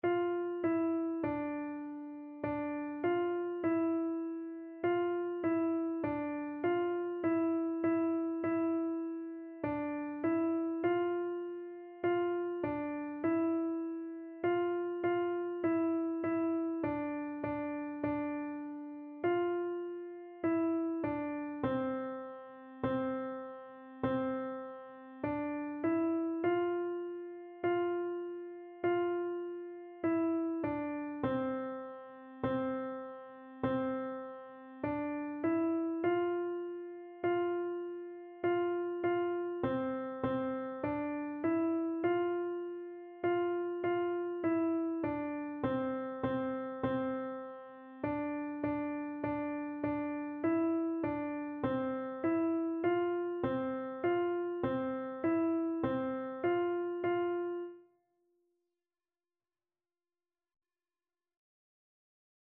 4/4 (View more 4/4 Music)
Beginners Level: Recommended for Beginners
Instrument:
Classical (View more Classical Piano Music)